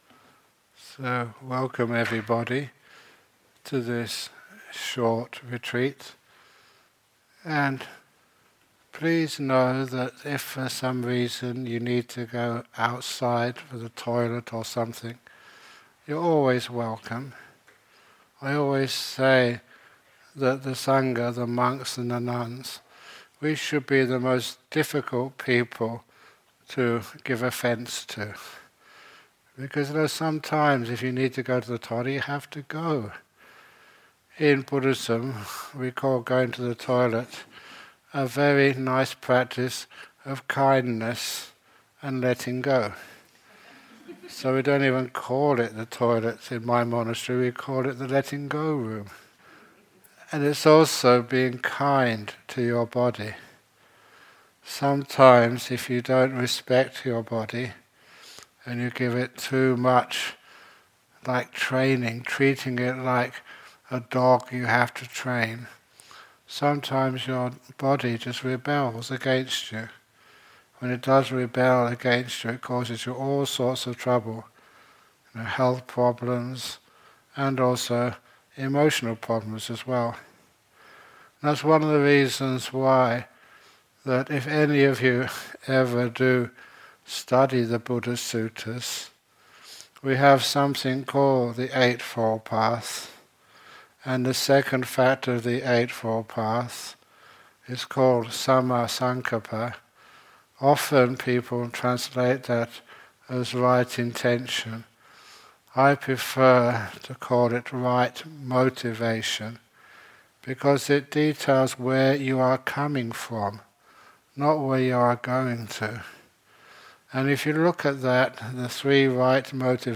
This is an introduction to meditation talk, given in Oslo in 2022.